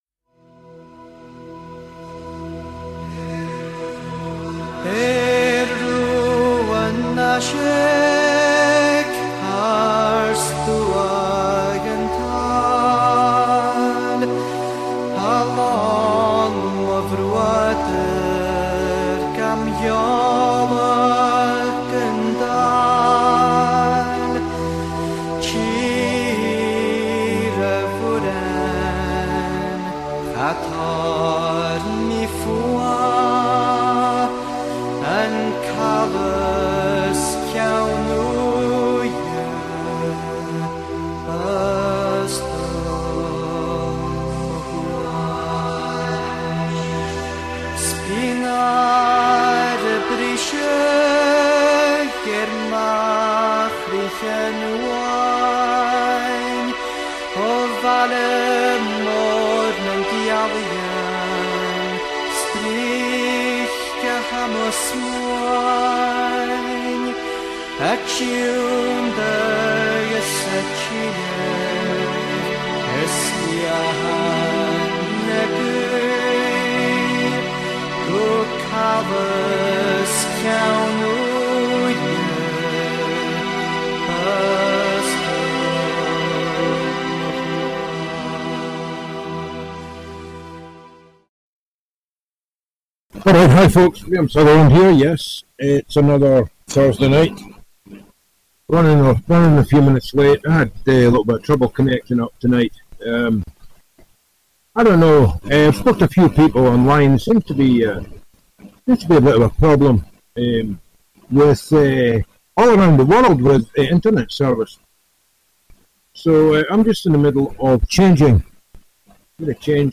Callers are welcome to contribute. This weekly radio show broadcasts live every Thursday from Inverness, Scotland, transmitting real, uncensored and unsanitized philosophy, news and perspectives.